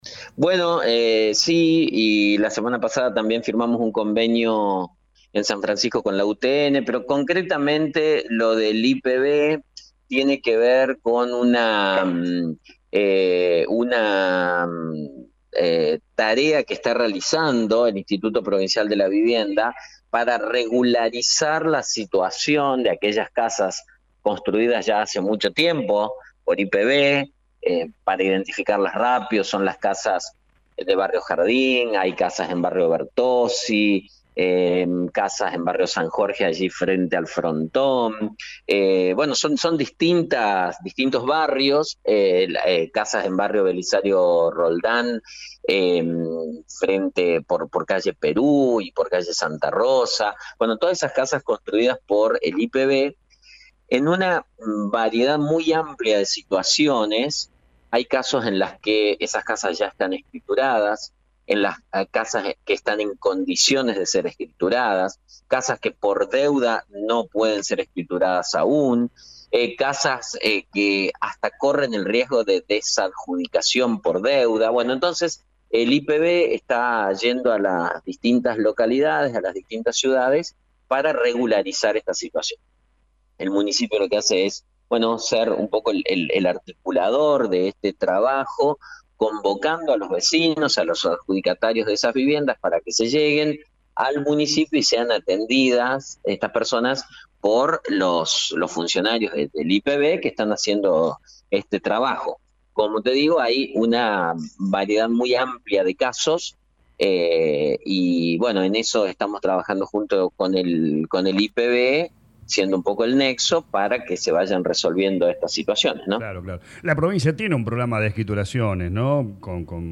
En diálogo con LA RADIO 102.9, el Intendente Mauricio Actis explicó que “la tarea que realiza el IPV es para regularizar la situación de casas en distintos barrios. Viviendas que están en una variedad amplia de situaciones: casas en condición de ser escrituras, otras con deudas, y casas que hasta corren el riesgo de desadjudicación por deuda”.